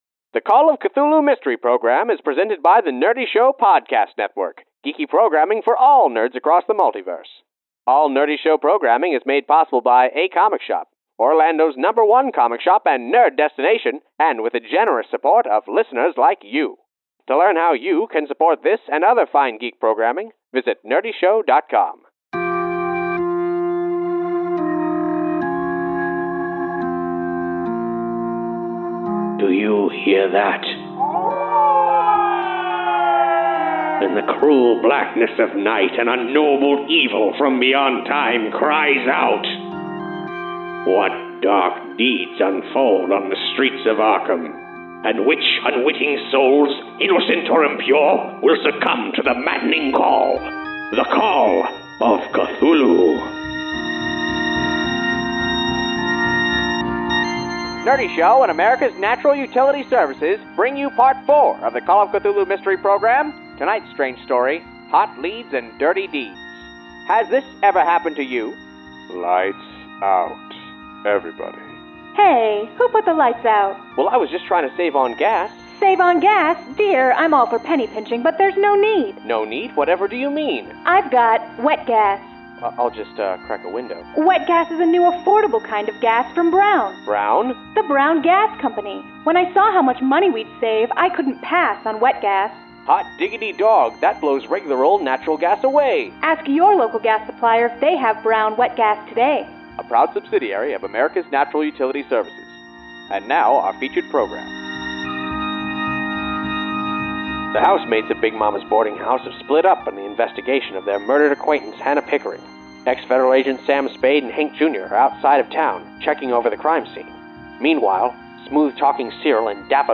The Call of Cthulhu Mystery Program is live tabletop roleplaying turned into a 1930s radio serial.